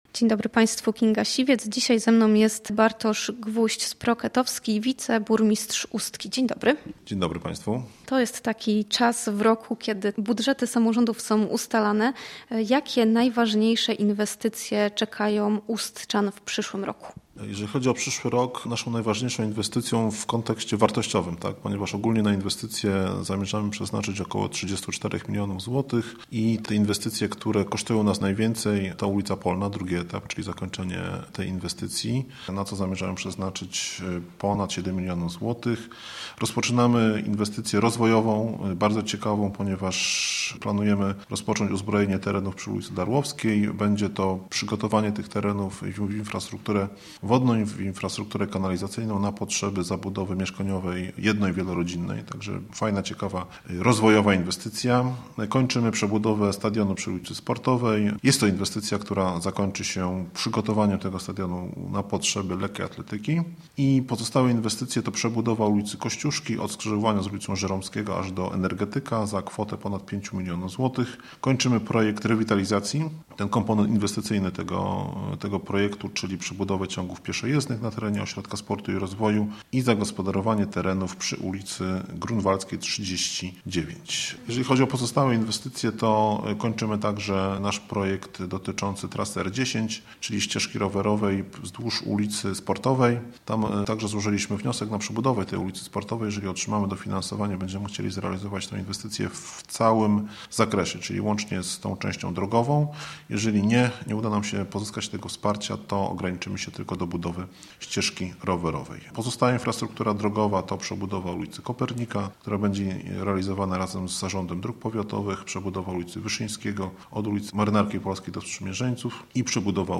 Więcej o planach na przyszły rok w rozmowie z zastępcą burmistrza Bartoszem Gwoździem-Sproketowskim.